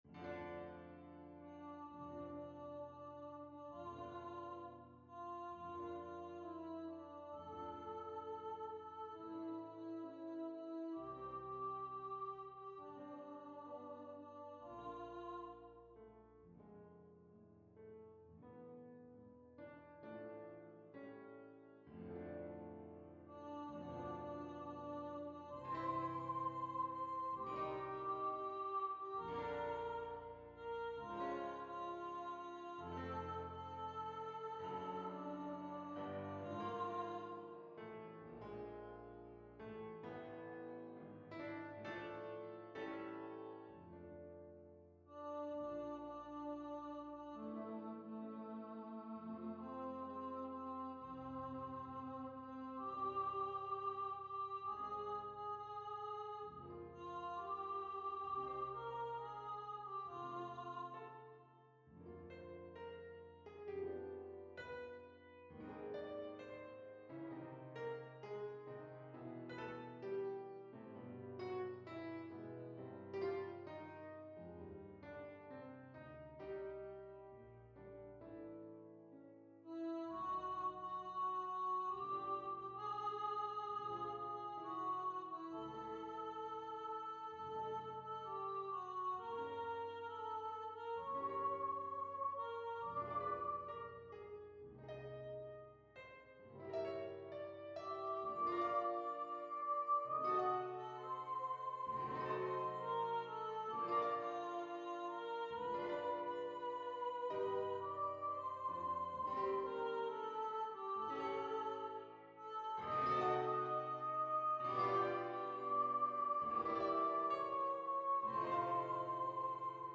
Voices: Solo Voice Instrumentation: Piano
NotePerformer 4 mp3 Download/Play Audio